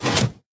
Minecraft Version Minecraft Version snapshot Latest Release | Latest Snapshot snapshot / assets / minecraft / sounds / tile / piston / in.ogg Compare With Compare With Latest Release | Latest Snapshot